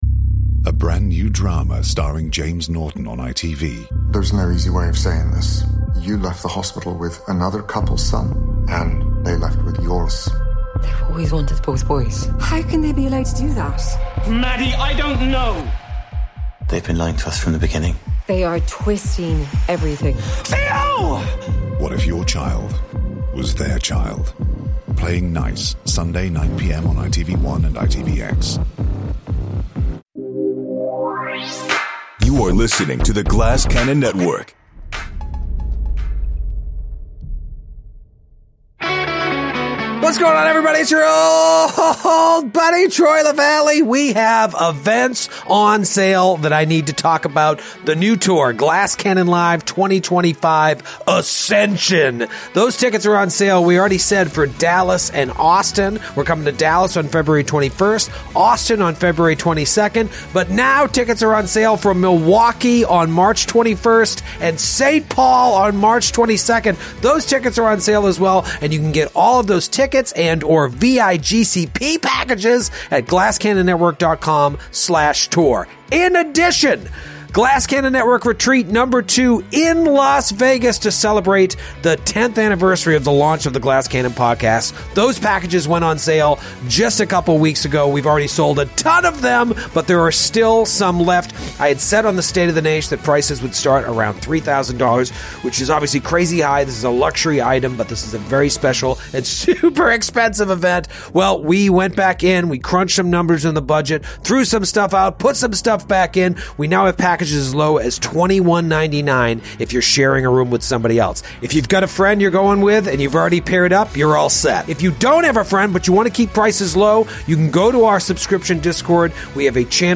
The guys take a trip down memory lane and talk with callers about their first video game memories.